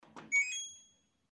Звуки стиральной доски